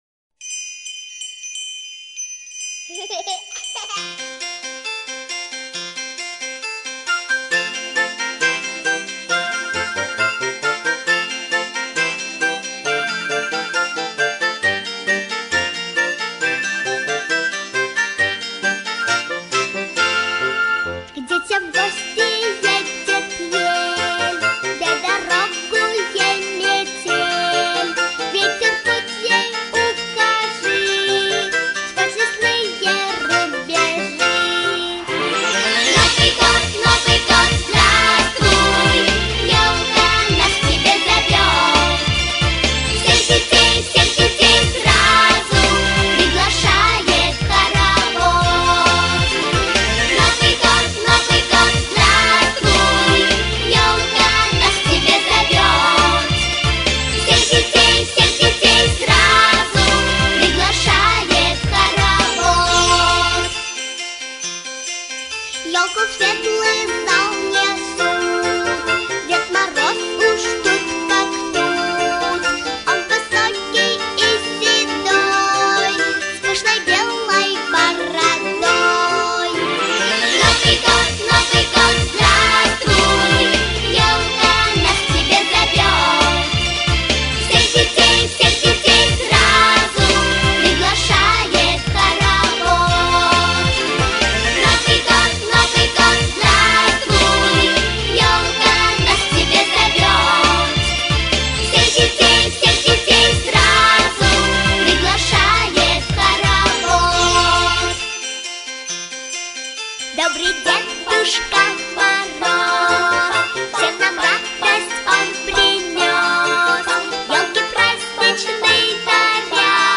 • Категория: Детские песни
хоровод